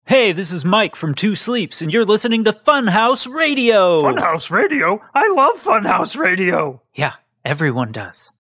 We are inviting our listeners to record station identification spots for us.
You can just use your video recorder on your phone, or you can get more fancy.